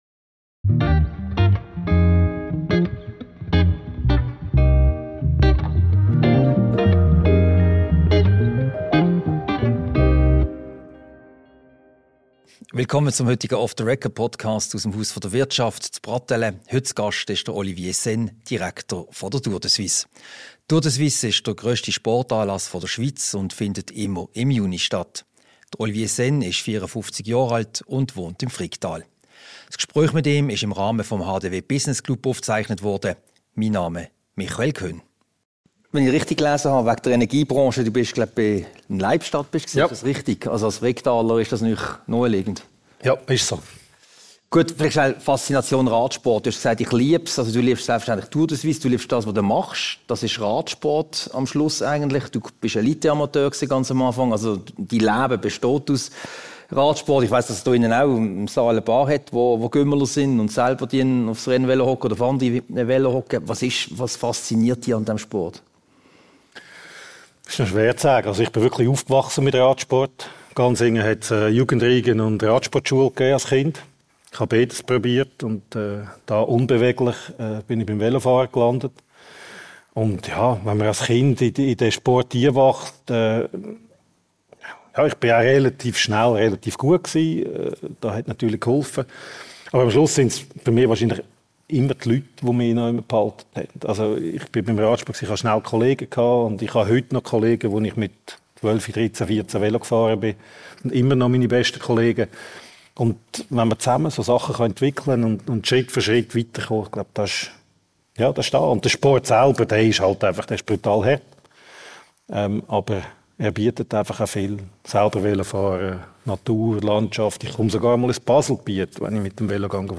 Diese Podcast-Ausgabe wurde anlässlich des HDW Business Club Lunchs am 6. November 2024 aufgezeichnet.